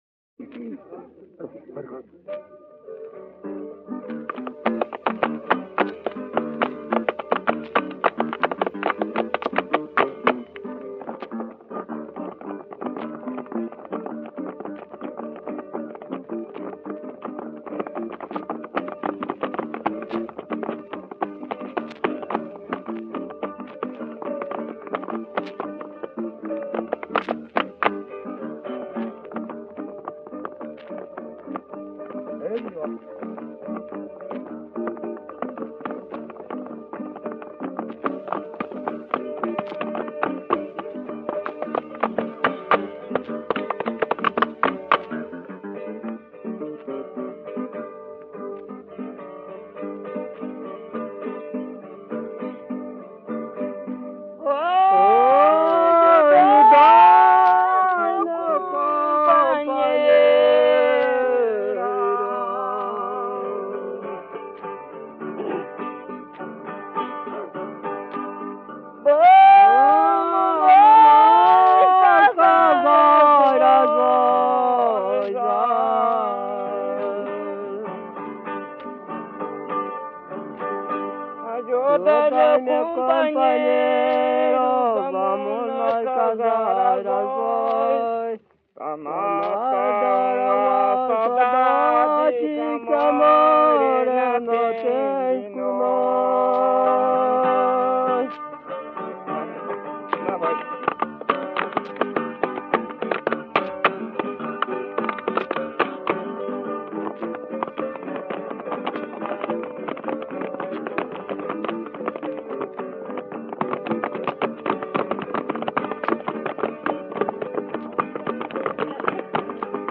Cateretê